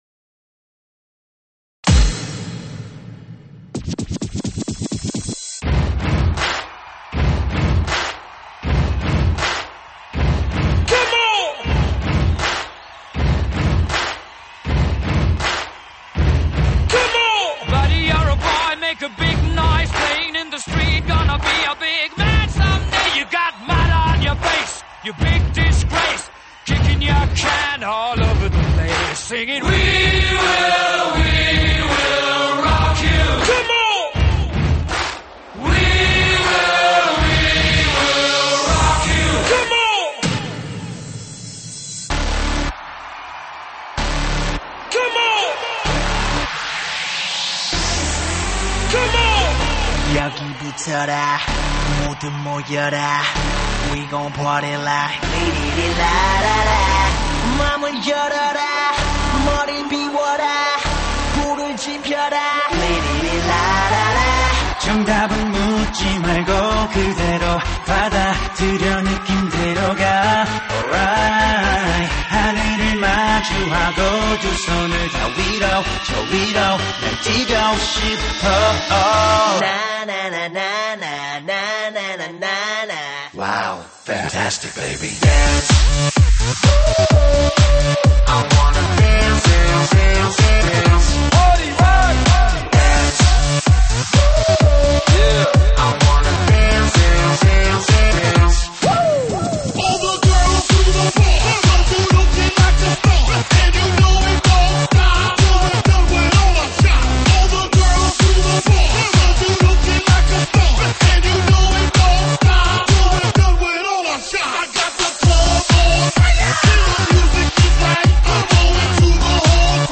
音质： 320 Kbps